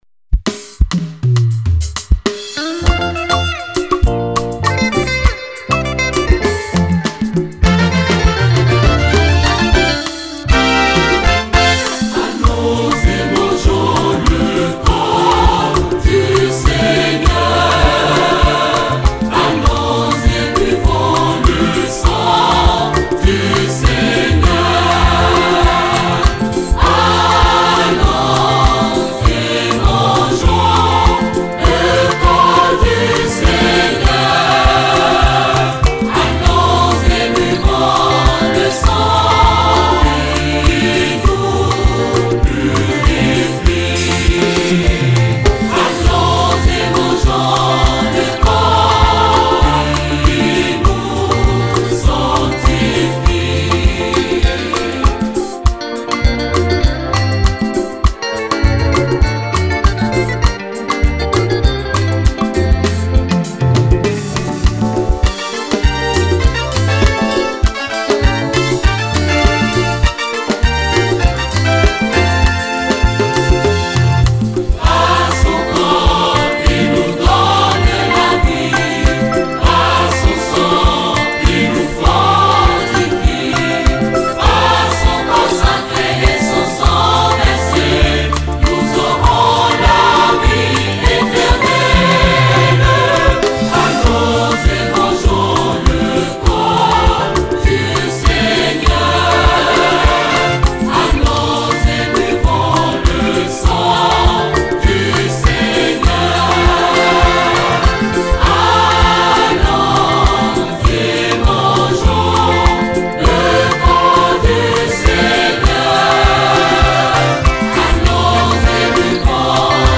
5.87Mo - Gospel Mp3 - T : 227 fois - E : 341 fois..